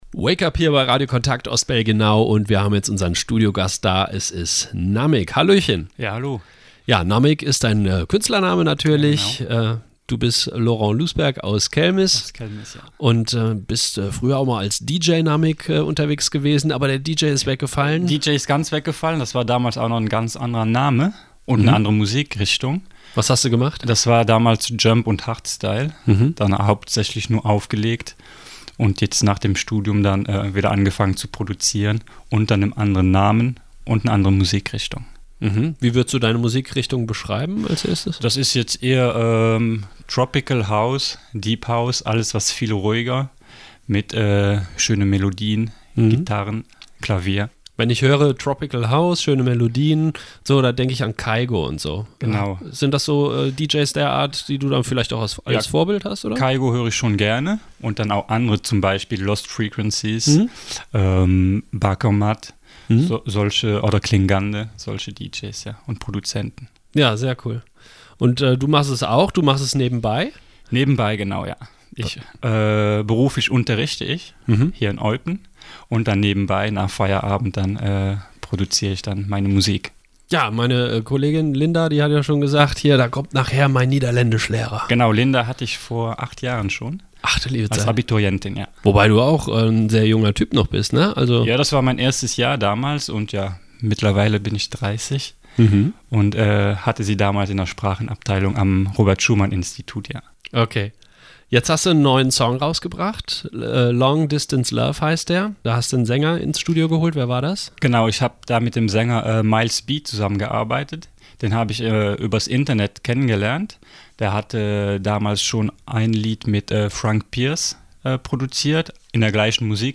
mit Studiogast